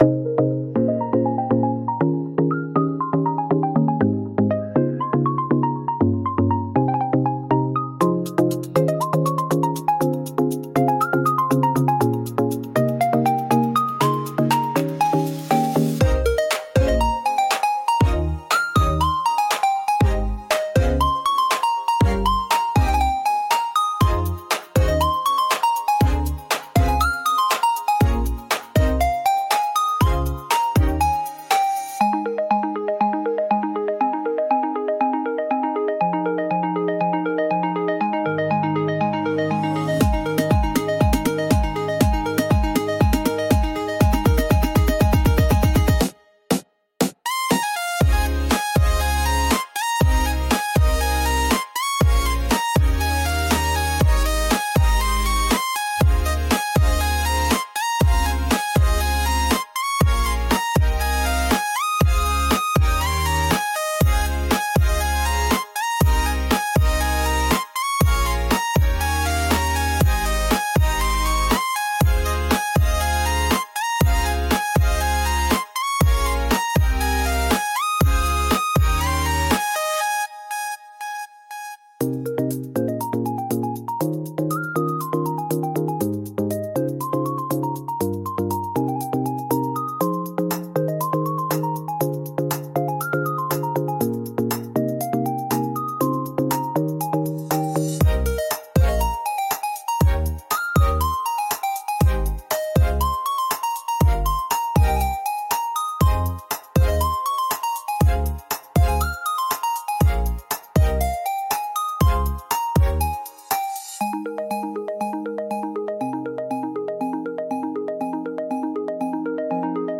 A♭ Major – 120 BPM
Electronic
Pop